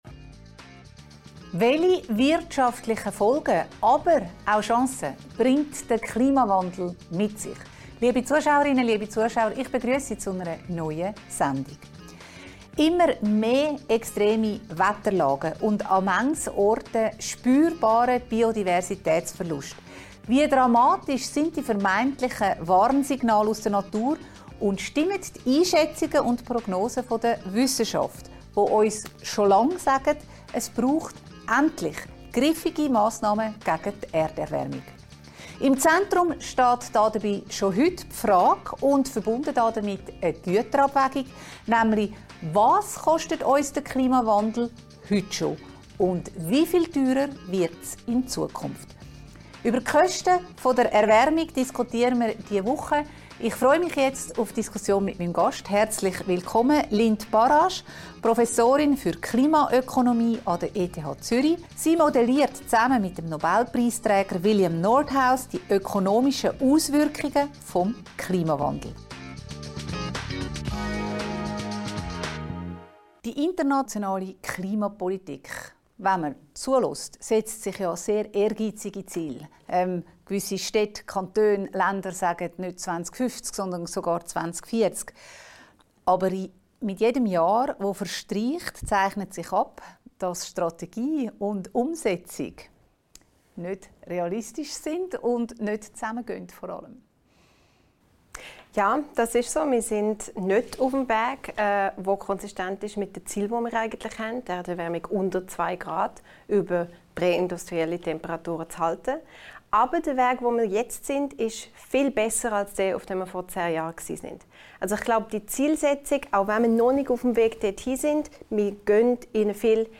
im Gespräch mit Klimaökonomin